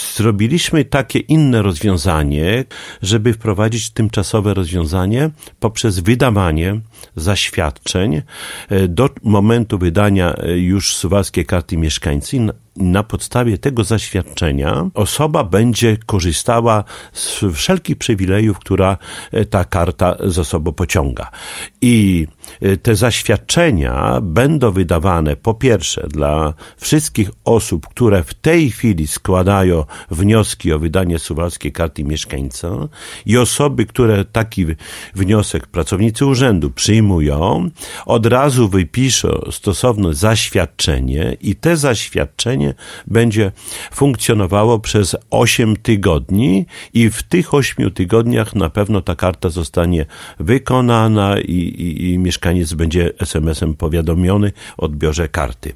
O szczegółach mówił w piątek (24.08.18) w Radiu 5 Czesław Renkiewicz, prezydent miasta.